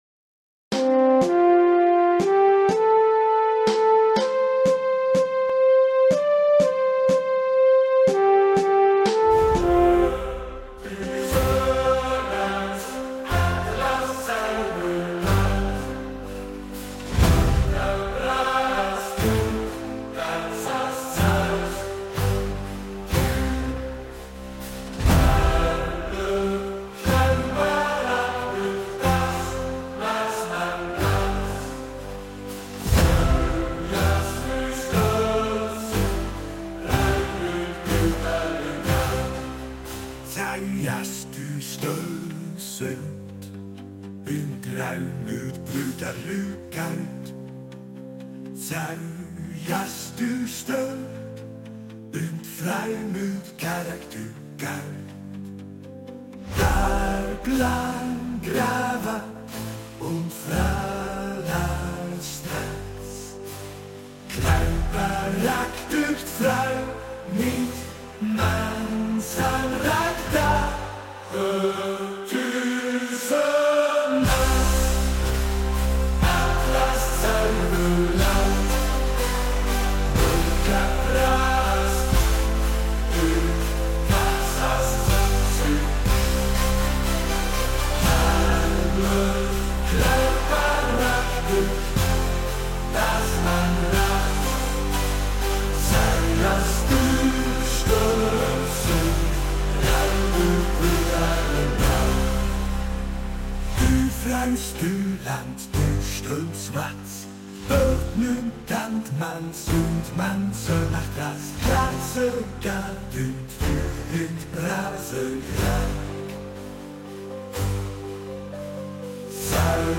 Nationalhymne